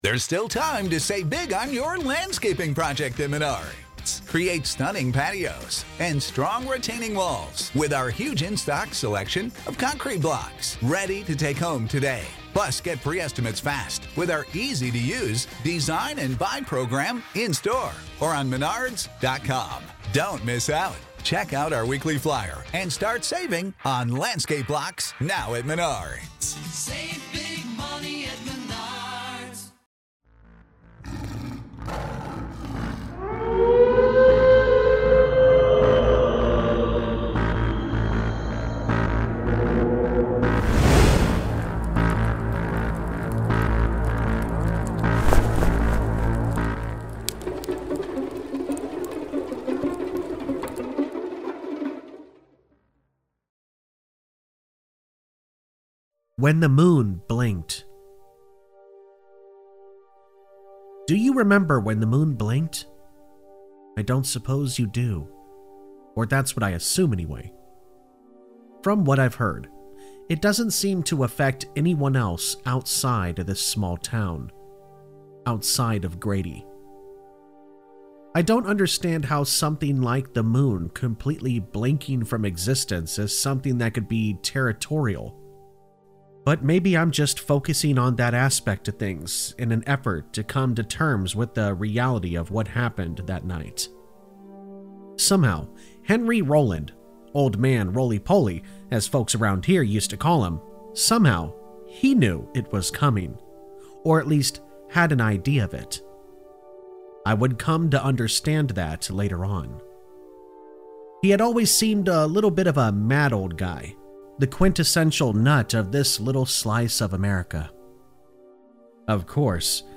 Campfire Tales is a channel that is human voiced that does NOT use a fake Ai voiced simulator program. This channel is focused on Allegedly True Scary Stories and Creepypastas.